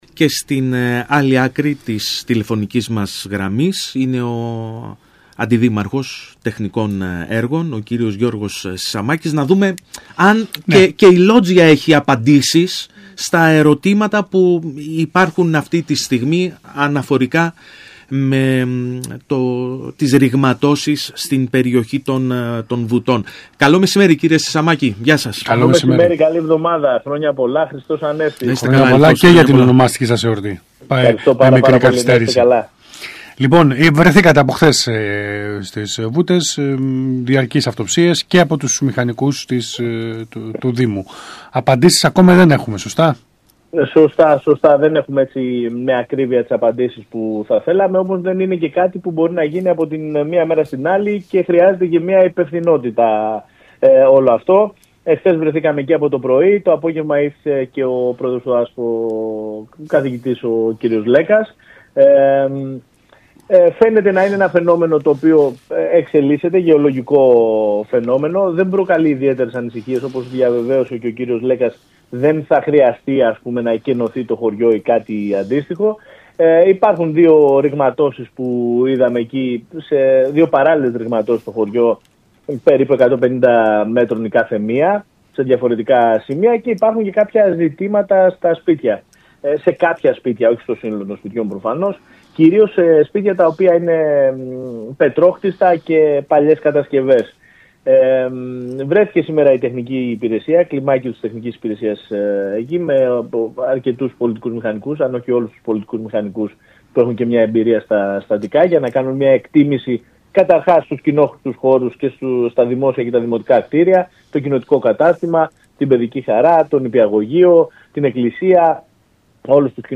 Ακούστε εδώ όσα είπε ο Αντιδήμαρχος Τεχνικών Έργων Γιώργος Σισαμάκης στον ΣΚΑΙ Κρήτης 92.1: